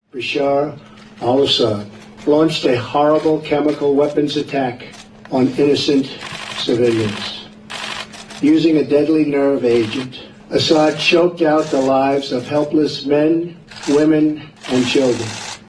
Zginęli mężczyźni kobiety i dzieci – mówi prezydent Trump.